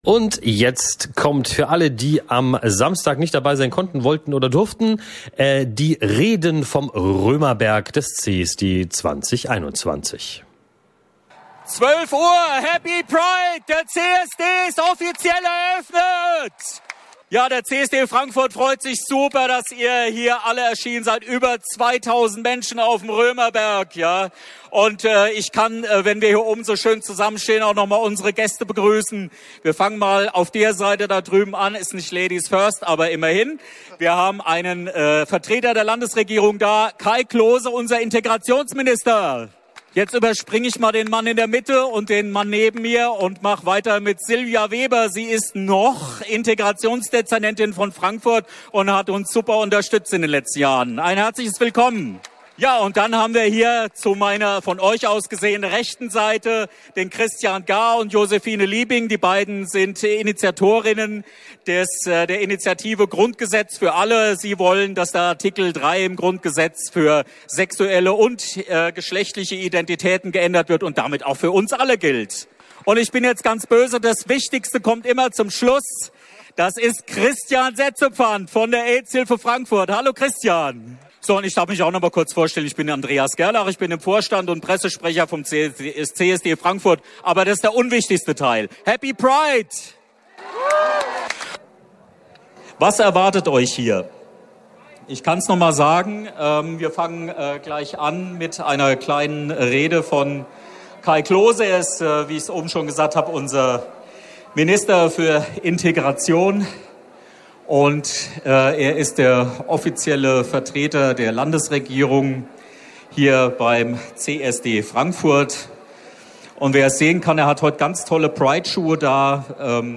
Sendung 1251: So war der CSD 2021 in Frankfurt – Die Reden auf dem Römer ~ radioSUB Podcast
Eröffnungskundgebung auf dem Römerberg